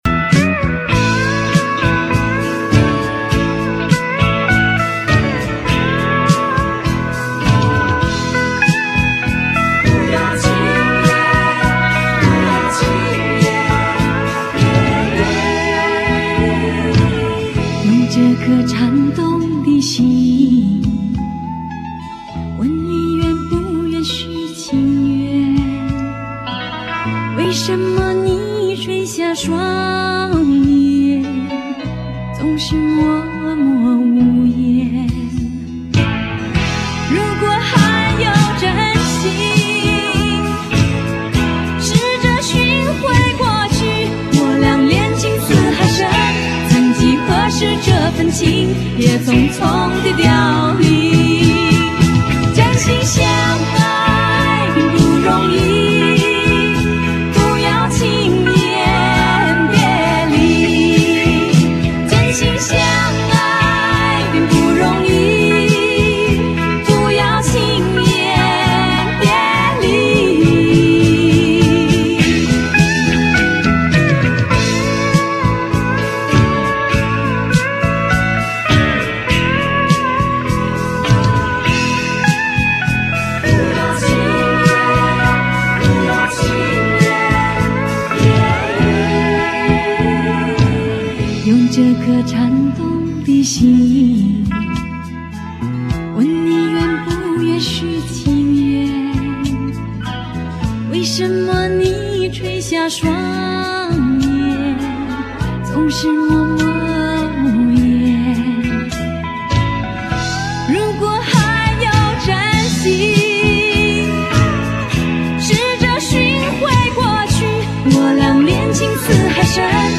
音乐类型：华语流行音乐